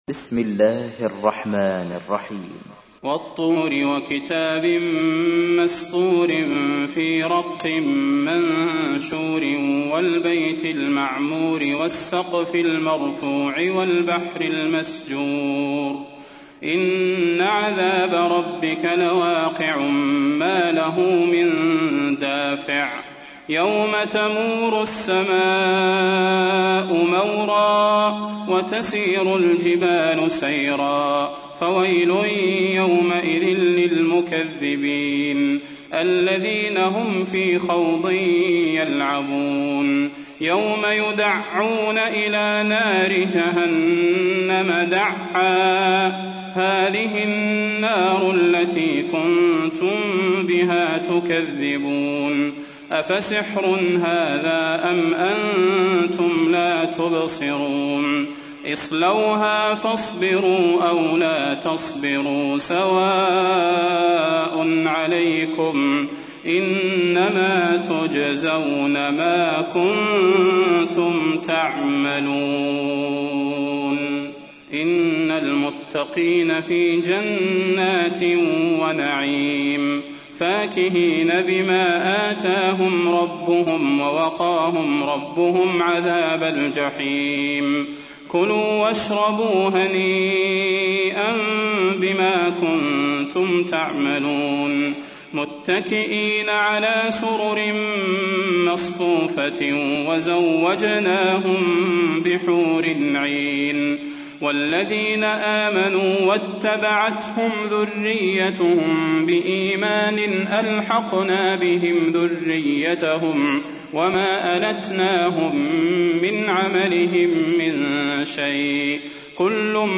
تحميل : 52. سورة الطور / القارئ صلاح البدير / القرآن الكريم / موقع يا حسين